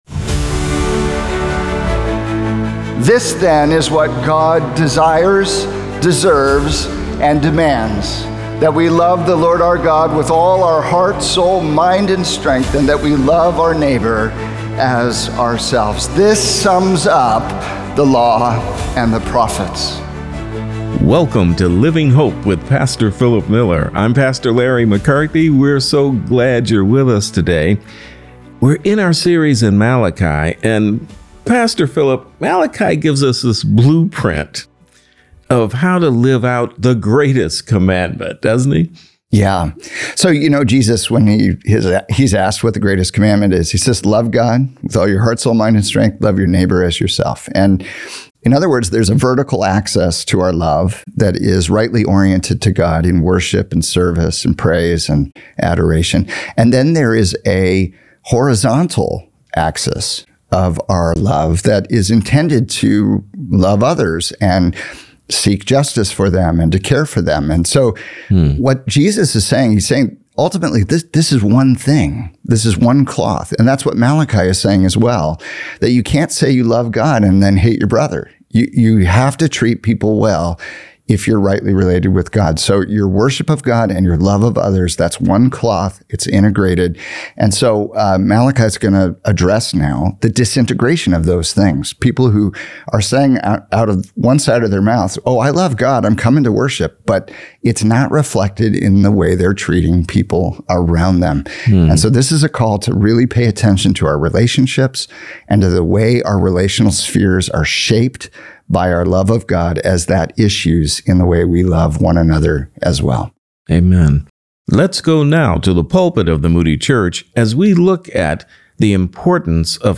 The Hidden Link Between Your Relationships and Your Faith | Radio Programs | Living Hope | Moody Church Media